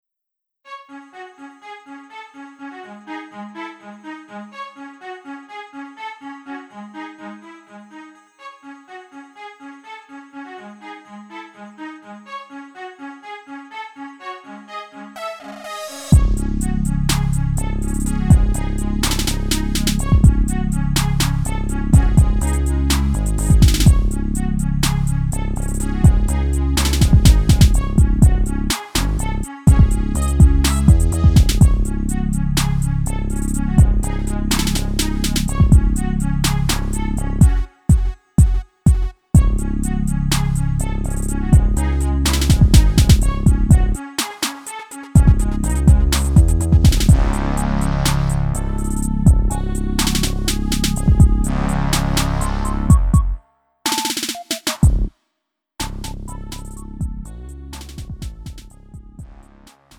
음정 원키 4:27
장르 가요 구분 Lite MR